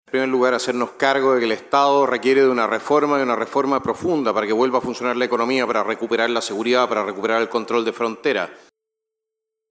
Sin mayores polémicas se desarrolló el panel presidencial en el Encuentro Nacional de la Empresa (Enade) 2025, en el que participaron siete de los ocho candidatos que buscan llegar a La Moneda.
Palabras finales de los candidatos
215-cu-presidencial-johannes-kaiser.mp3